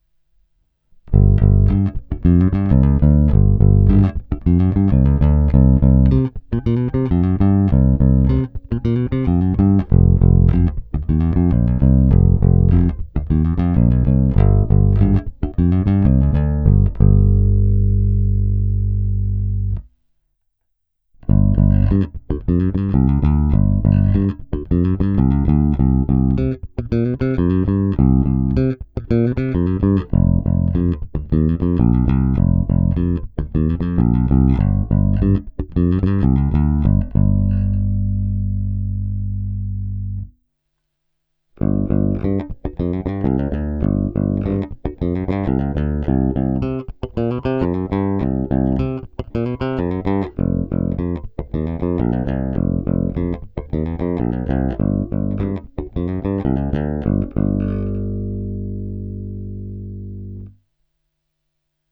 Baskytara má dostatek středů umožňujících jí se prosadit v kapele a zároveň tmelit zvuk.
Není-li uvedeno jinak, následující nahrávky jsou provedeny rovnou do zvukové karty, jen normalizovány, jinak ponechány bez úprav.